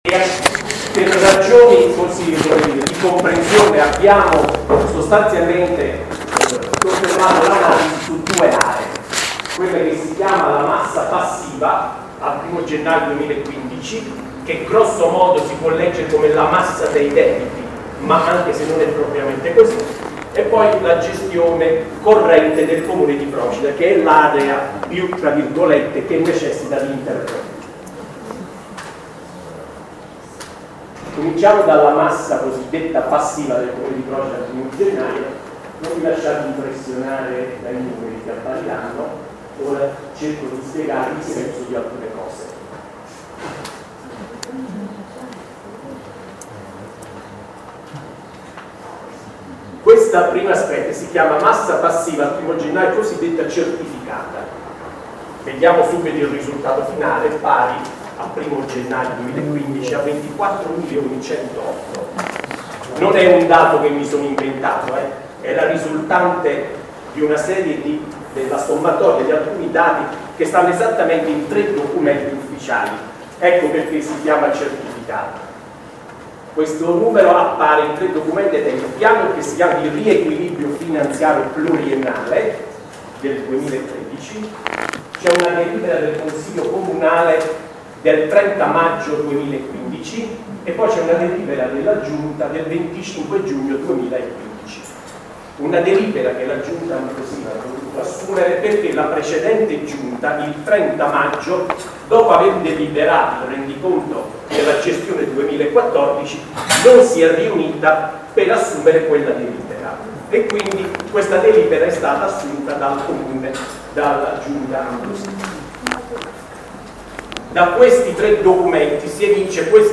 Un nostro amico, che ringraziamo, ci ha inviato il resoconto audio dell’incontro che si è tenuto domenica 12 luglio alle ore 18,30 al Comune di Procida. Integralmente, così come ricevuto, proponiamo questo importante documento, a beneficio dei cittadini, che vede l’esposizione dettagliata sulla situazione economica dell’Ente Locale fatta dal consigliere delegato al bilancio Giovanni Villani, che si è soffermato particolarmente sulle passività dell’Ente, dall’assessore con delega al contenzioso Antonio Carannante, che ha specificato le vertenze aperte e del peso che potranno avere, e dal Sindaco Dino Ambrosino.